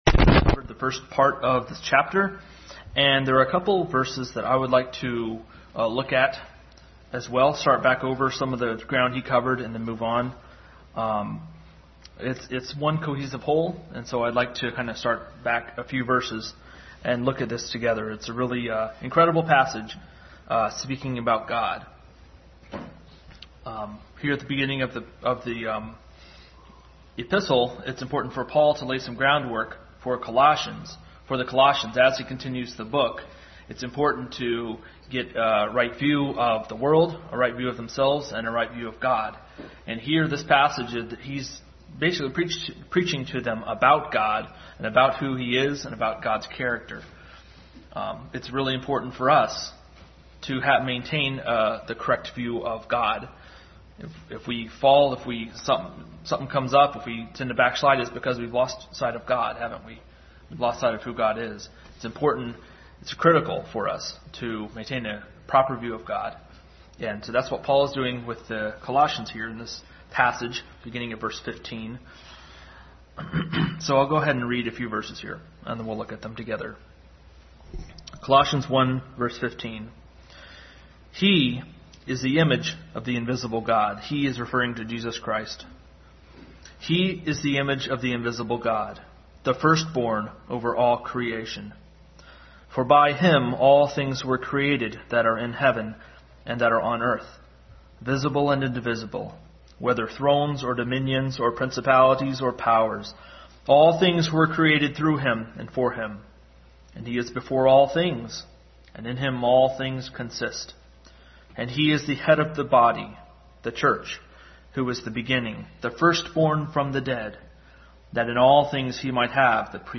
Adult Sunday School continue study in Colossians.